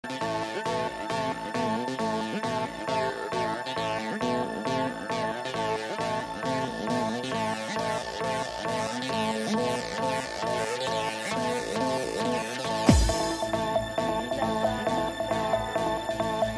Рабочий на проходе пулеметит. Железо забивает остальной барабан..
В начале вроде как такой нехилый всплеск, и потом провал - странно, мож ты с компрессором на мастер секции намудрил?